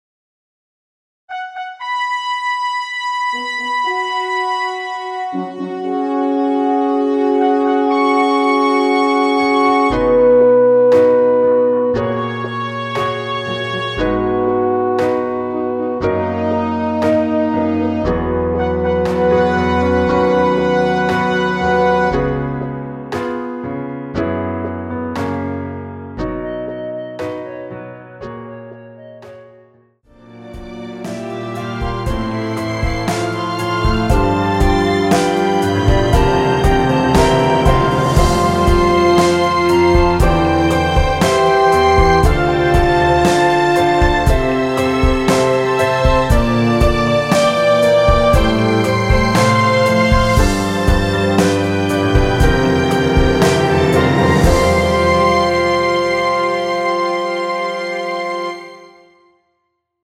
엔딩이 너무 길어 라이브에 사용하시기 좋게 짧게 편곡 하였습니다.(원키 미리듣기 참조)
원키에서(+6)올린 멜로디 포함된 MR입니다.
앞부분30초, 뒷부분30초씩 편집해서 올려 드리고 있습니다.
중간에 음이 끈어지고 다시 나오는 이유는